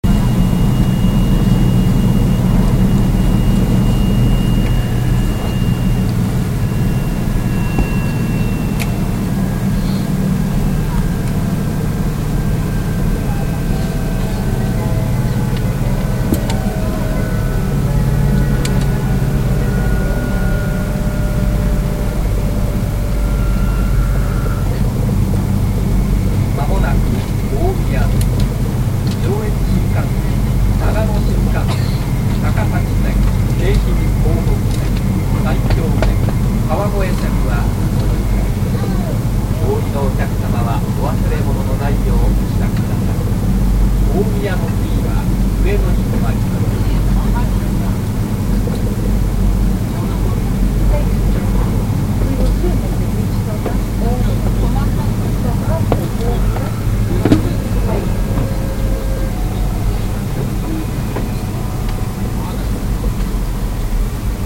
はやて20号 3020B 東北幹 TECE2-1000 大宮到着前。
早めにデッキに立っていたので、走行音が強めで放送は聞こえにくい。